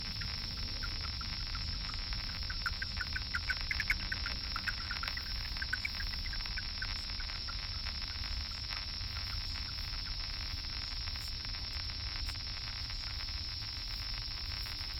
Audio of a Big Brown Bat, Eptesicus fuscus, captured with an Echo Meter Touch 2 outside my back door just now.